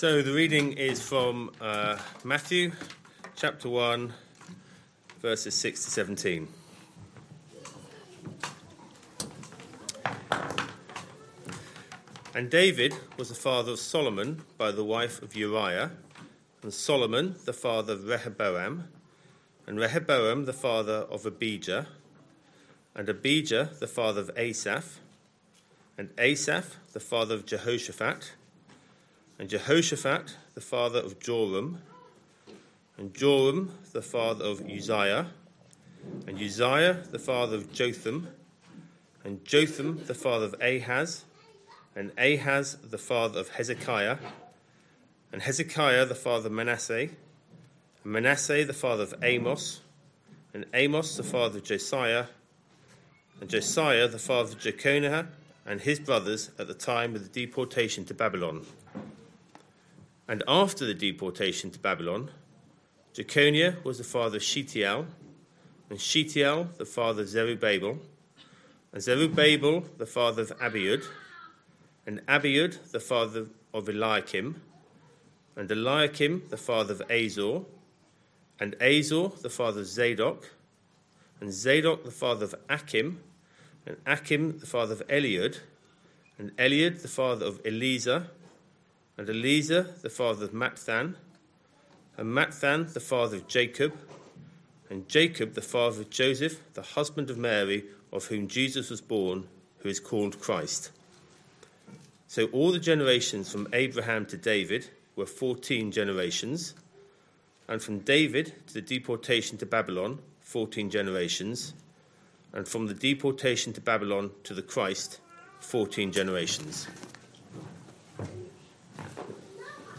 Sunday AM Service Sunday 14th December 2025 Speaker